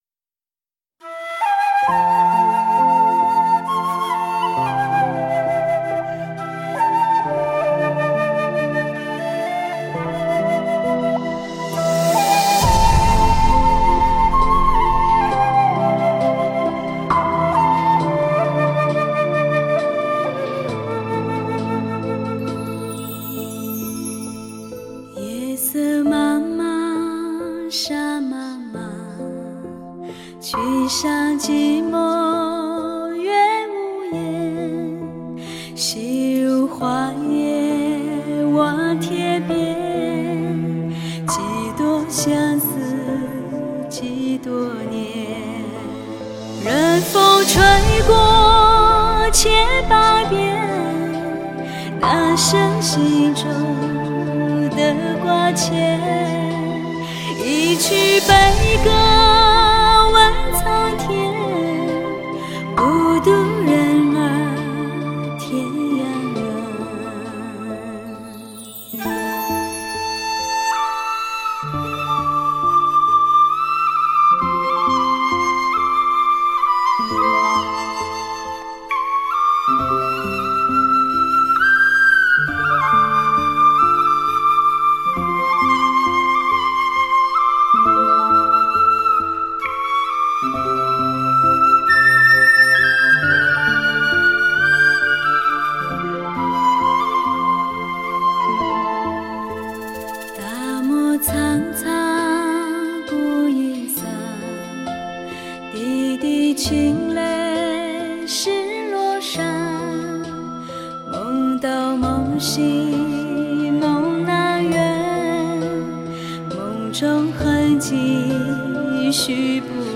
HD直刻无损高音质音源技术，HIFI限量珍藏版。
本专辑是一张最具民族色彩的发烧专辑。
游动的弦乐，雄鹰翱翔的女声，融入神秘原始部落和声，一切皆是美景......